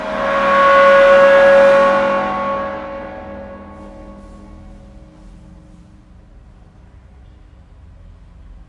金属制品 " 金属爆炸01
描述：软打在铜质雕塑上
Tag: 命中 金属